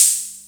Closed Hats
Wu-RZA-Hat 3.wav